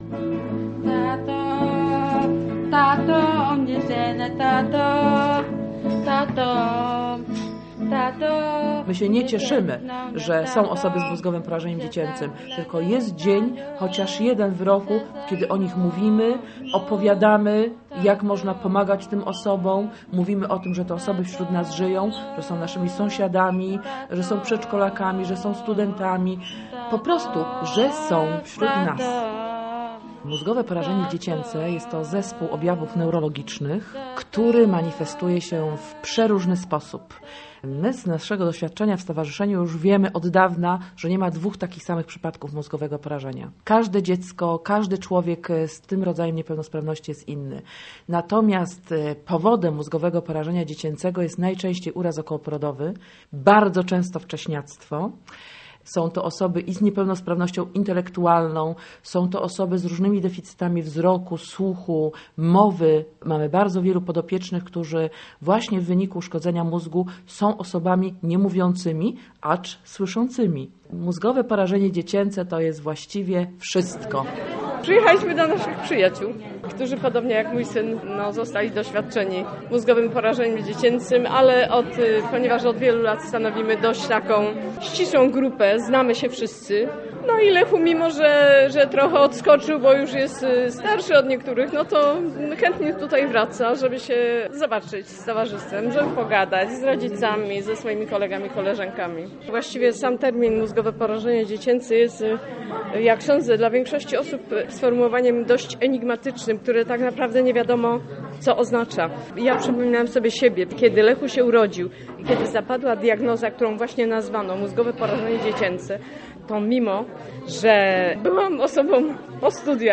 Porażeni - reportaż